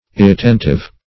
irretentive - definition of irretentive - synonyms, pronunciation, spelling from Free Dictionary
Irretentive \Ir`re*ten"tive\, a.